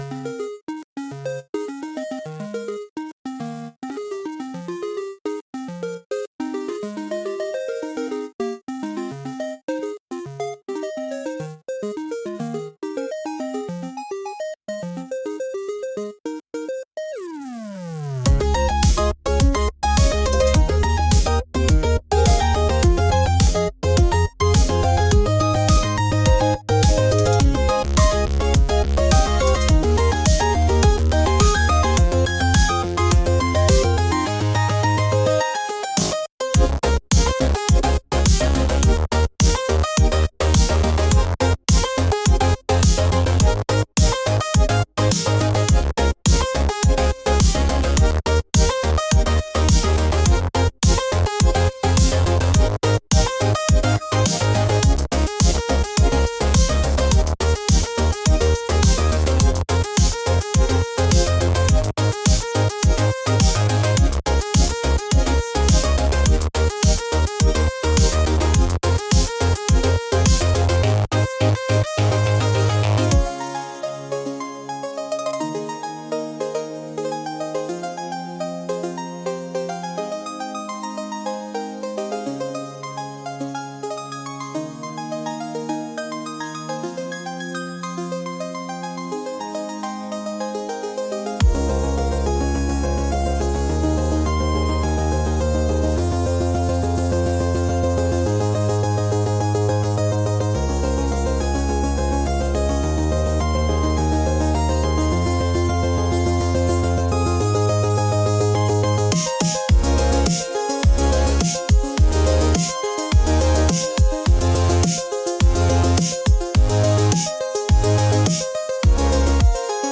Genre: Digicore Mood: Glitchy Editor's Choice